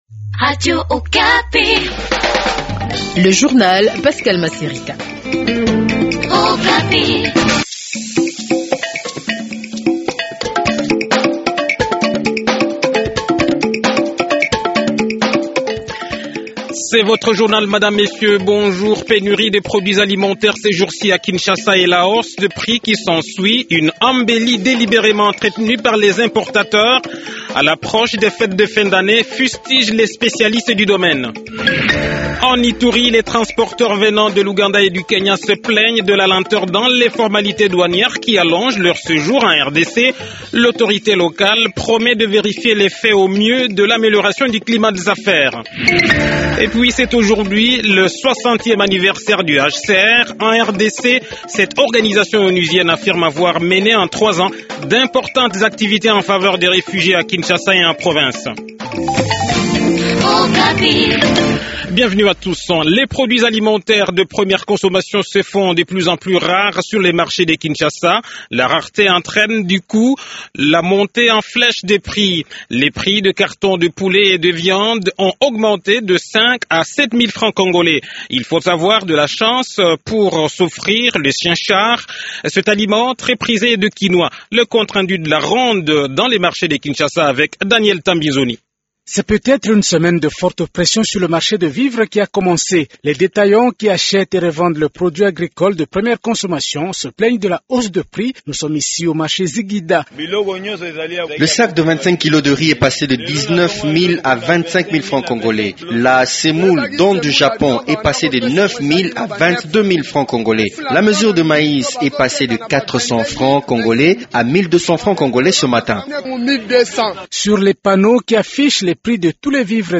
Journal Français Matin